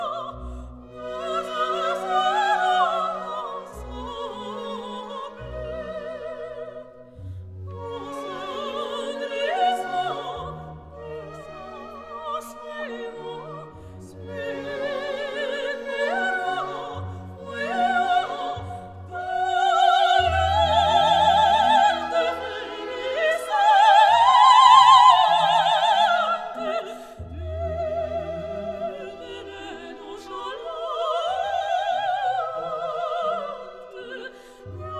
Жанр: Классика